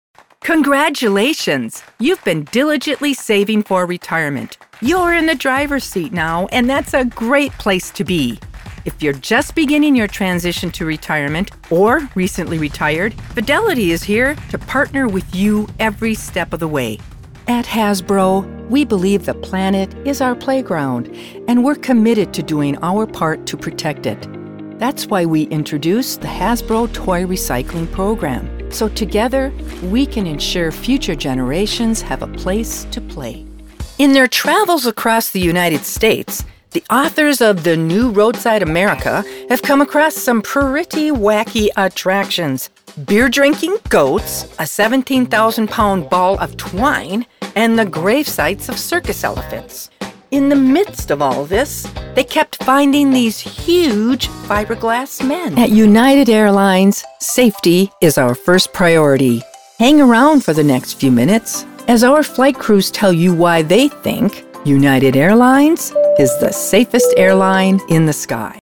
Narration Demo